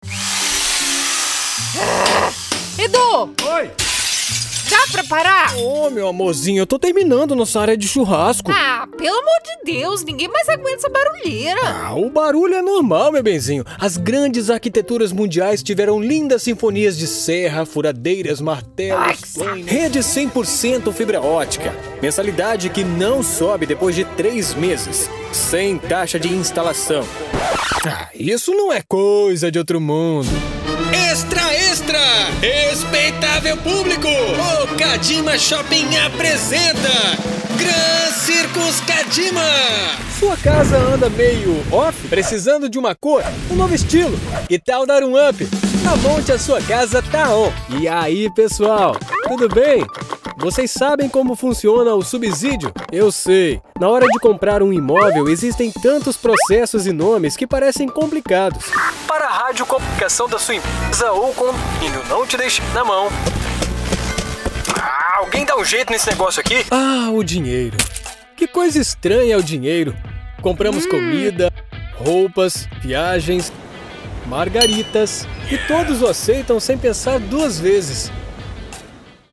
Masculino
Voz Caricata 01:19
Além de equipamentos profissionais devidamente atualizados, todas as locuções são gravadas em cabine acústica, resultando em um áudio limpo e livre de qualquer tipo de interferência.